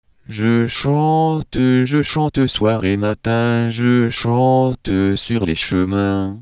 MBROLAchante.wav